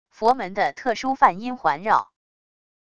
佛门的特殊梵音环绕wav音频